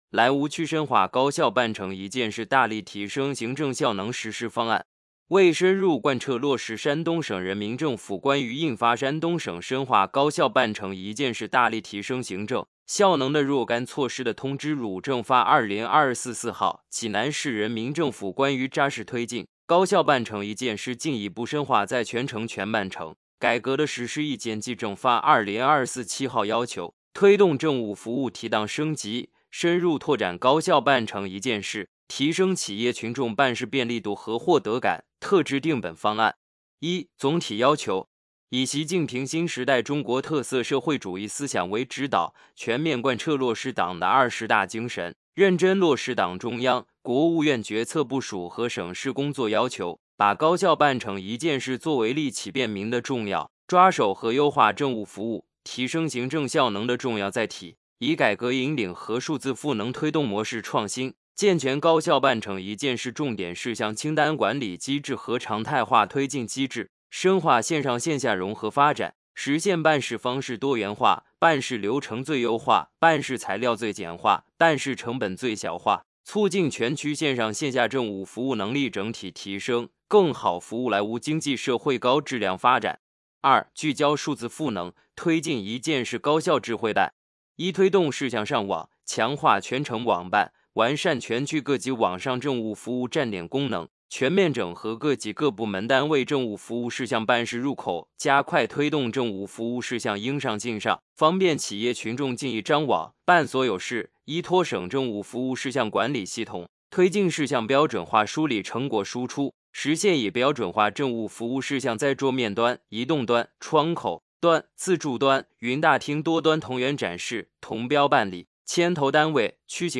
有声朗读：《莱芜区深化“高效办成一件事”大力提升行政效能实施方案》